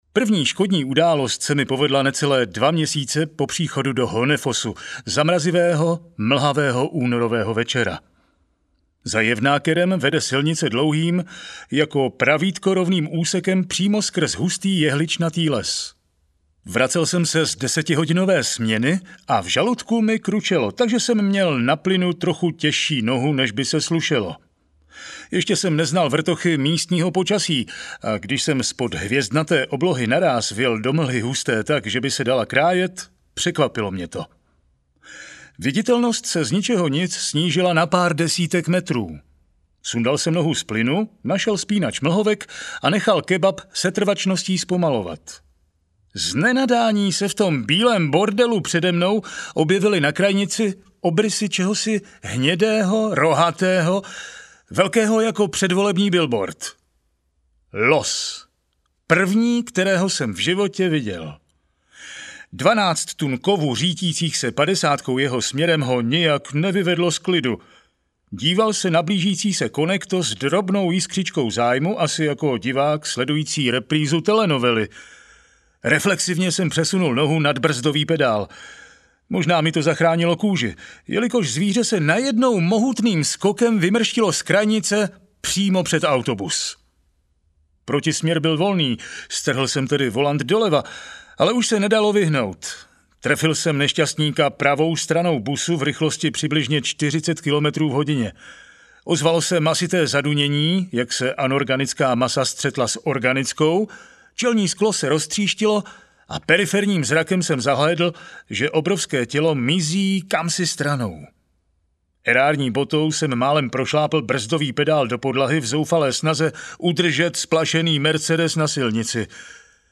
Jak jsem vozil Nory audiokniha
Ukázka z knihy